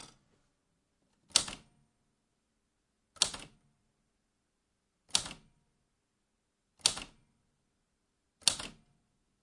打字机慢
描述：在Addo 621打字机上打字。用索尼HiMD录音机和AT828立体声话筒录制。
Tag: 按键 机器 攻丝 文本 打字机 打字